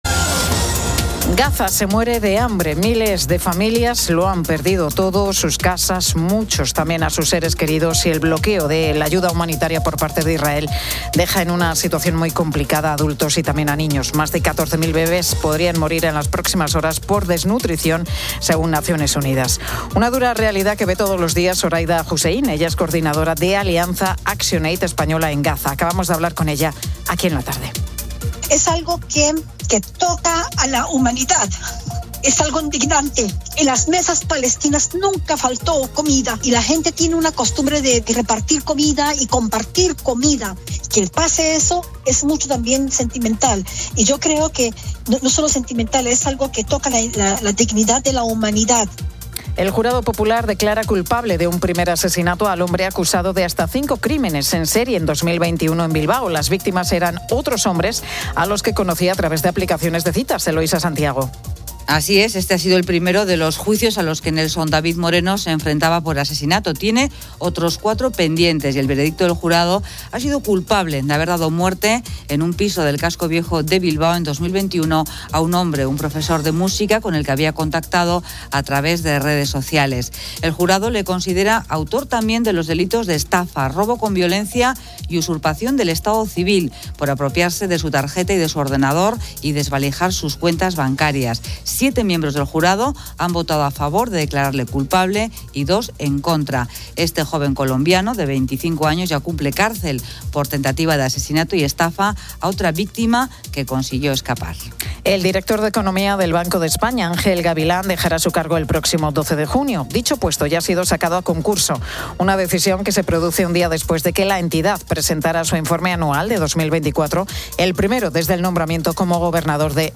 La Tarde 17:00H | 21 MAY 2025 | La Tarde Pilar García Muñiz entrevista a El Kanka, que inicia su gira acústica "Las Canciones".